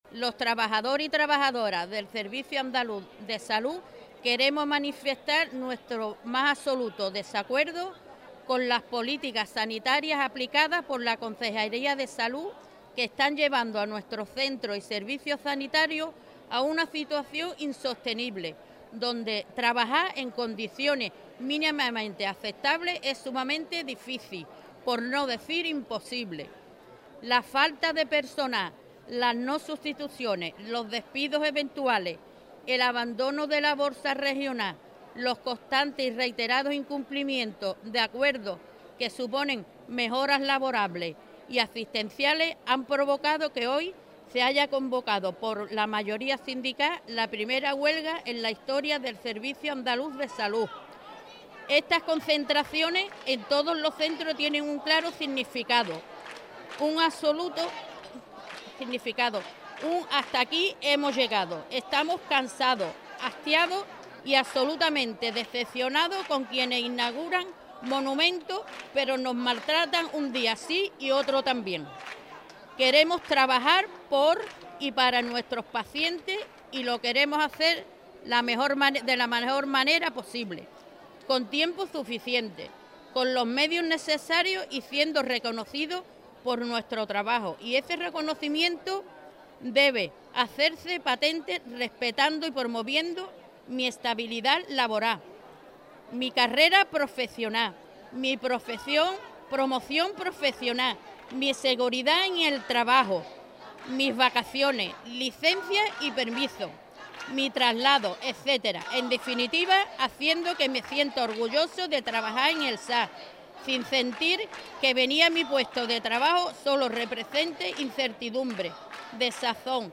Trabajadores del SAS de toda Andalucía, con el apoyo de los sindicatos, han protagonizado una concentración a las puertas de los centros sanitarios hoy, miércoles, para exigir que la Junta de Andalucía cumpla con los compromisos en la atención primaria y en los derechos de los empleados públicos de todas las categorías y que afectan a la sanidad pública.
CONCENTRACION_SANIDAD_TOTAL_MANIFIESTO.mp3